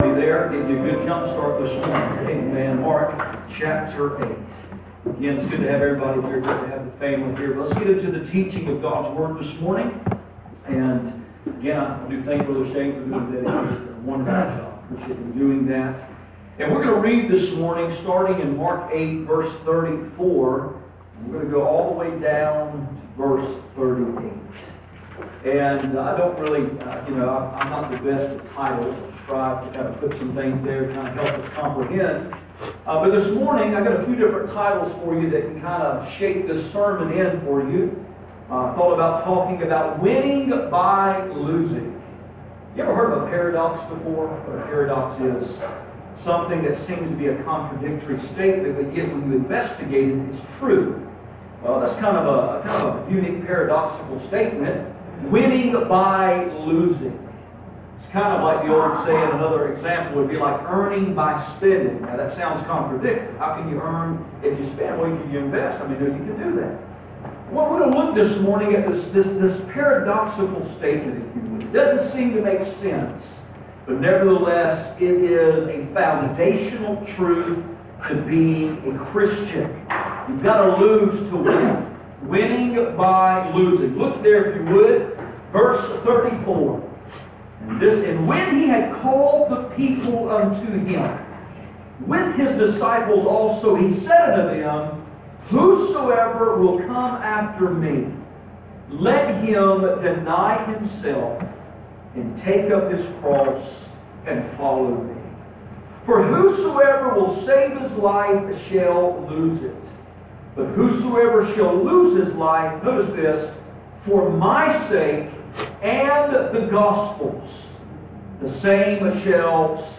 None Passage: Mark 8:34-38 Service Type: Sunday Morning %todo_render% « Desperate times call for desperate measures Are you Spiritual